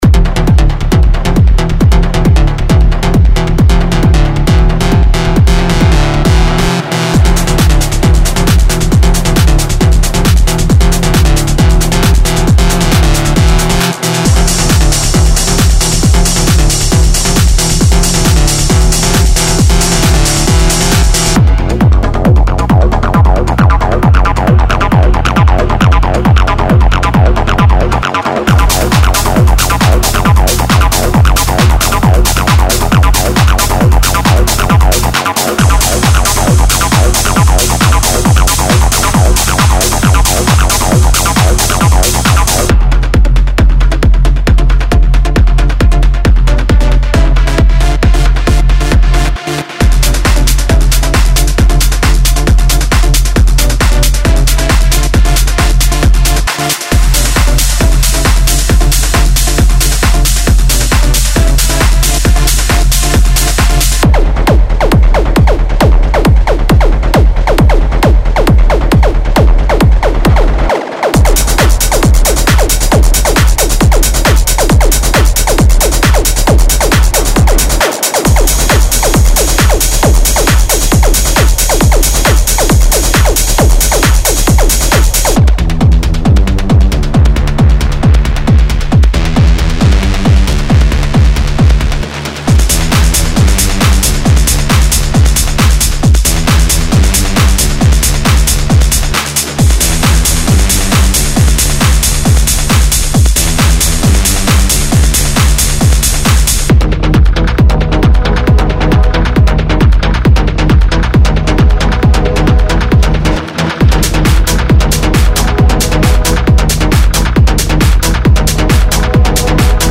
Genre:Techno
シンセループは催眠的なシーケンスから攻撃的なスタブまで幅広く、トラックに勢いを与えるのに最適です。
デモサウンドはコチラ↓